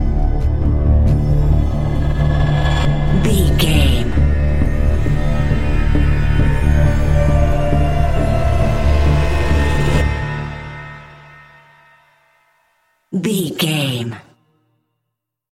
Aeolian/Minor
D
synthesiser
percussion
ominous
dark
suspense
haunting
tense
creepy